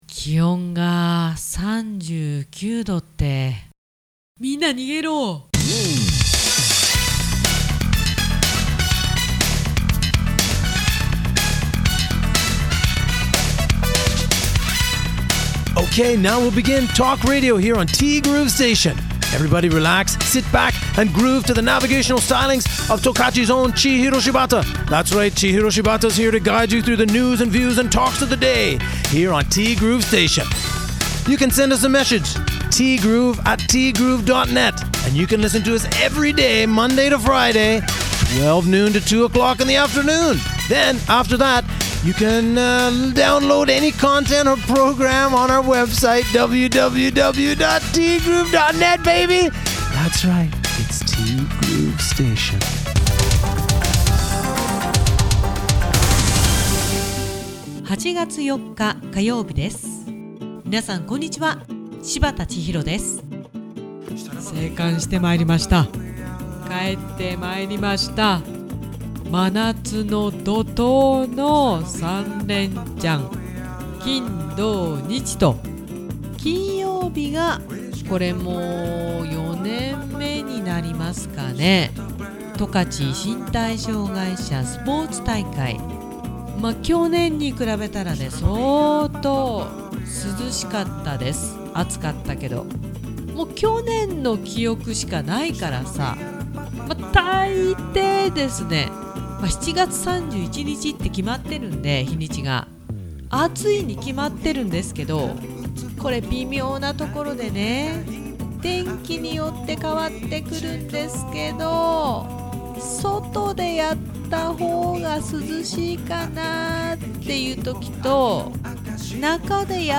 声も枯れたっす・・・。